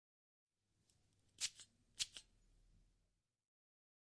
Nasal Spray Sound Effect by freesound_community from Pixabay – Pixabay License